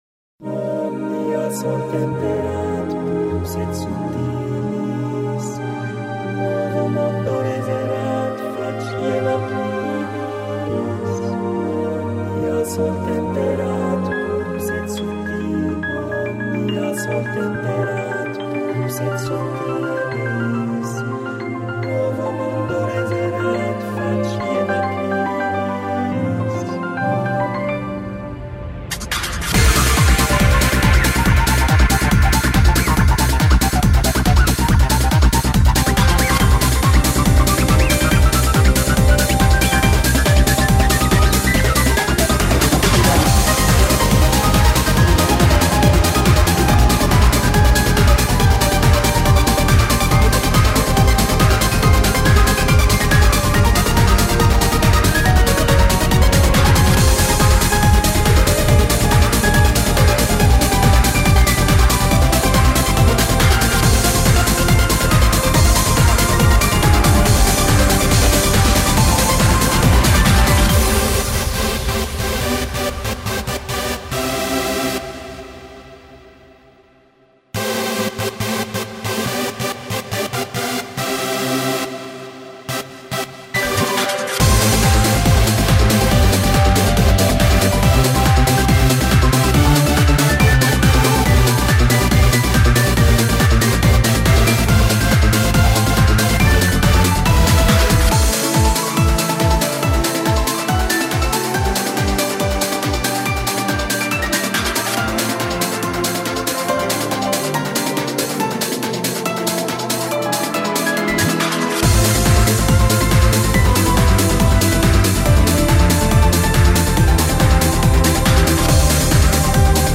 BPM70-140
Audio QualityPerfect (High Quality)
Genre: ANTHEM TRANCE REMIX